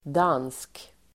Uttal: [dan:sk]